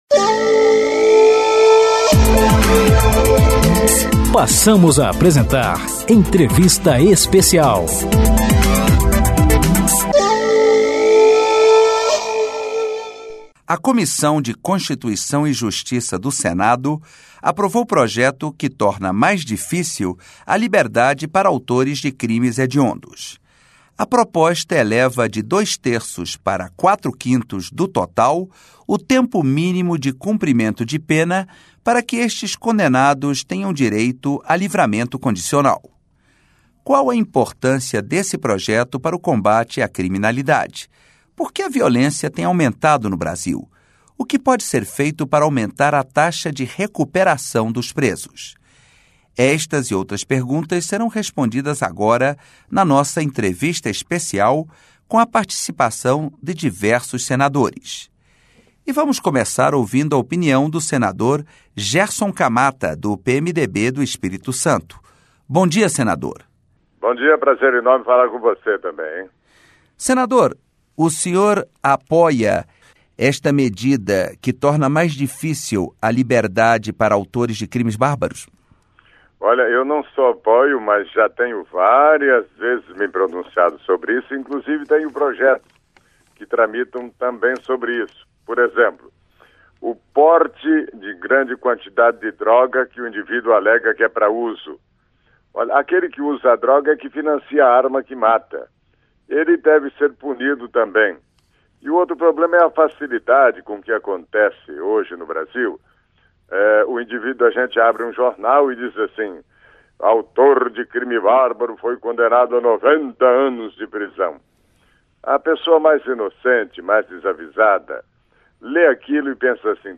Entrevista com os senadores Gerson Camata (PMDB-ES), João Faustino (PSDB-RN) e Pedro Simon (PMDB-RS).